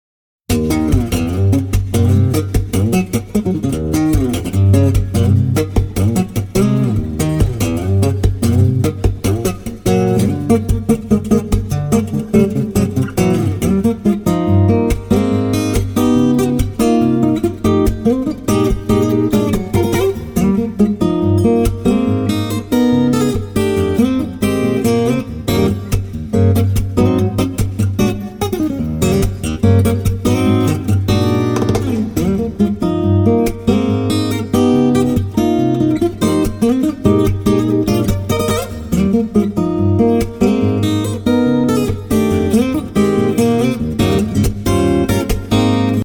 たった１本のギターが織りなす音世界・・・それはまるでオーケストラ。
「原点回帰」の意味を込めて、全15曲中14曲をスタンダード・チューニングで演奏。
グルーブ感に溢れ、豊かに広がりのある新しいサウンドに注目。